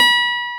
CLAV E5.wav